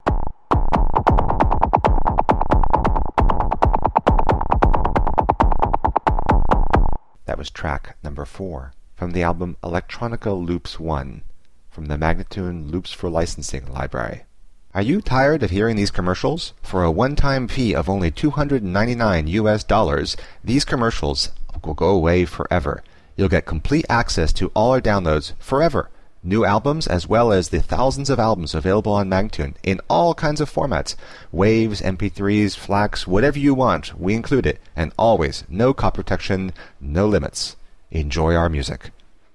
Instrumental samples in many genres.
135-C-ambient:teknology-1027